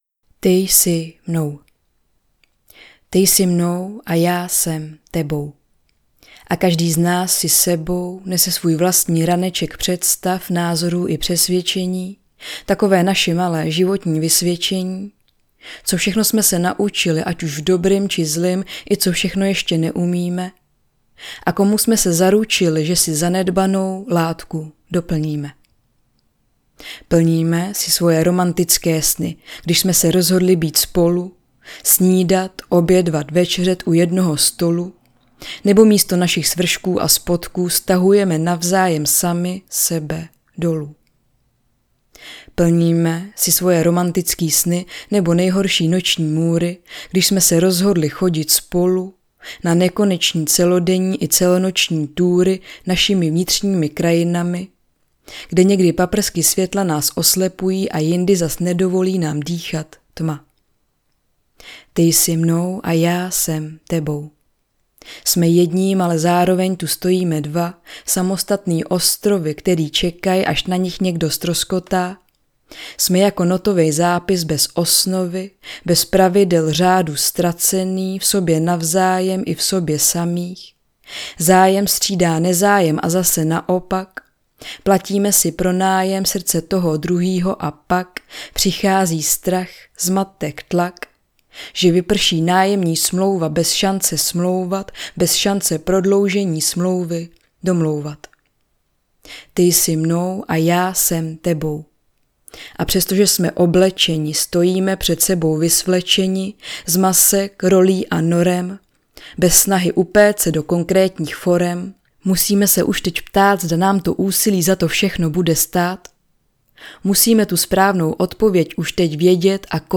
Za okraj... audiokniha
Ukázka z knihy
Pochází ze žánru slam poetry, což je performativní autorská poezie. Ve své tvorbě se snažím jít do hloubky, zaměřuju se na stíny naší společnosti i osobní témata.